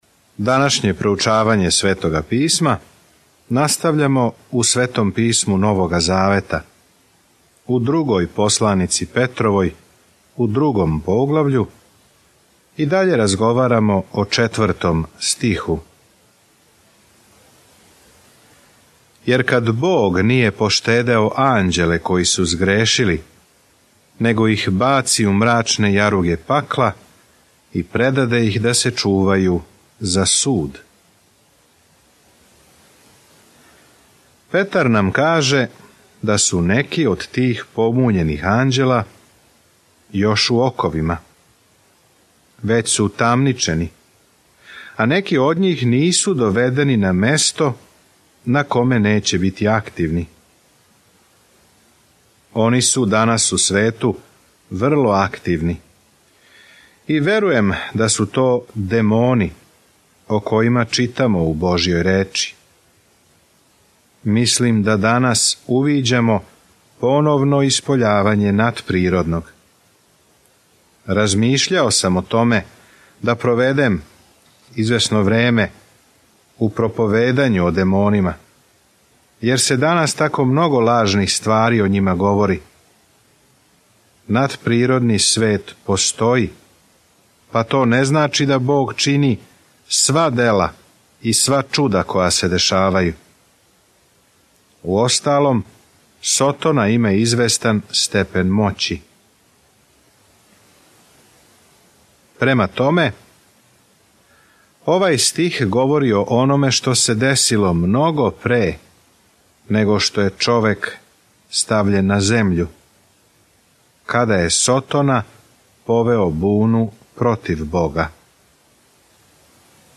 Petrova 2:5-8 Dan 8 Započni ovaj plan Dan 10 O ovom planu Друго Петрово писмо говори о Божјој милости – како нас је спасила, како нас чува и како можемо да живимо у њој – упркос ономе што лажни учитељи говоре. Свакодневно путујте кроз 2. Петрову док слушате аудио студију и читате одабране стихове из Божје речи.